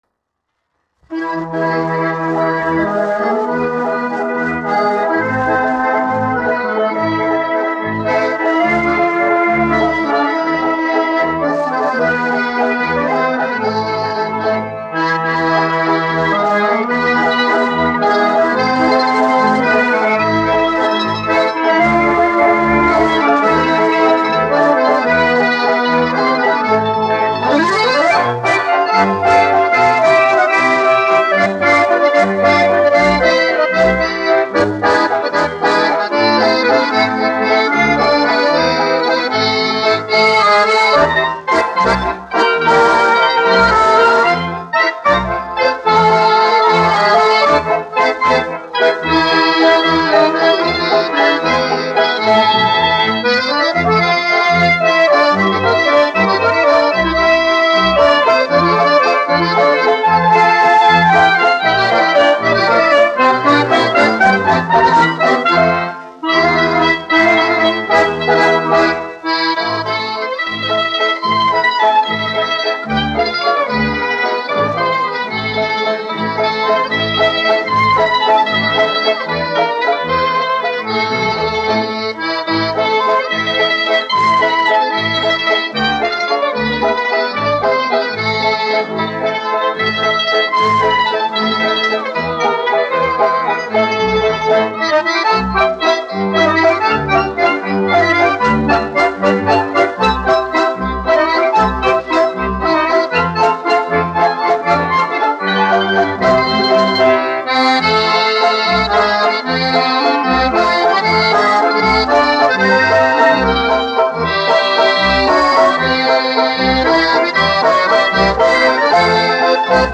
1 skpl. : analogs, 78 apgr/min, mono ; 25 cm
Orķestra mūzika
Skaņuplate